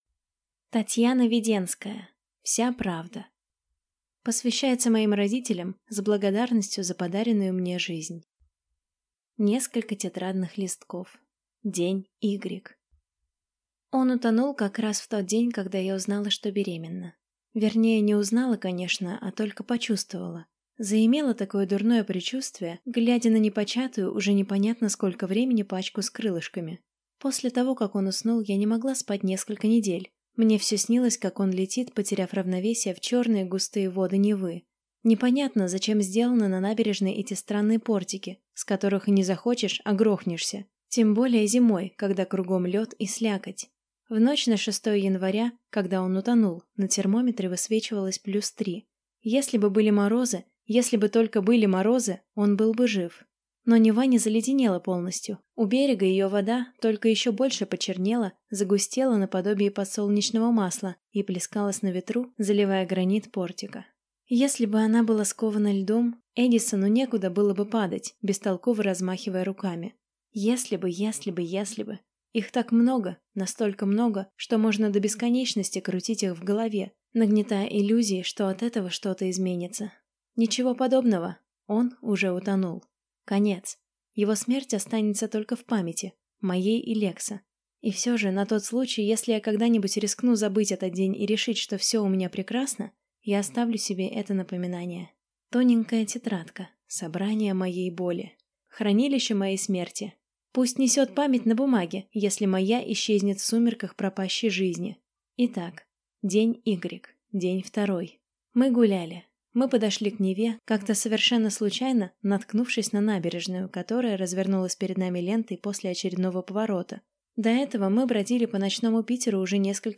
Аудиокнига Вся правда | Библиотека аудиокниг